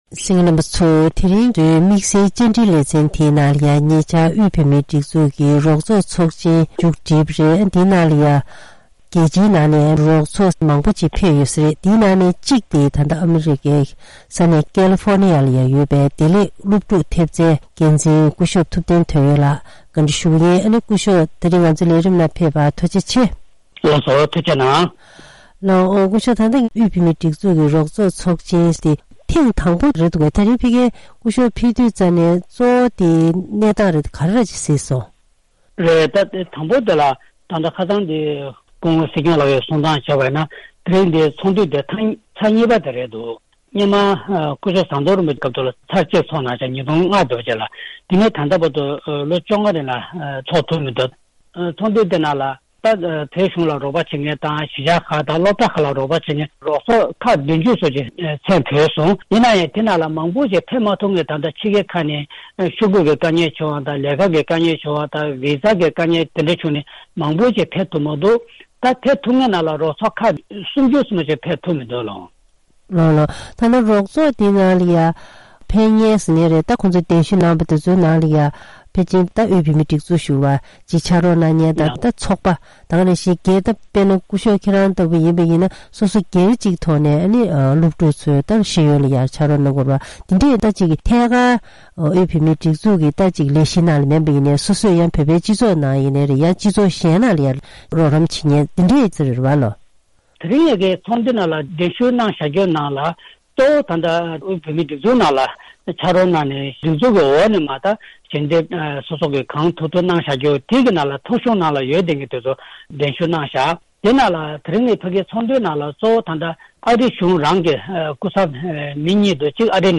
ཐེངས་འདིའི་དམིགས་བསལ་བཅར་འདྲིའི་ལེ་ཚན་ནང་།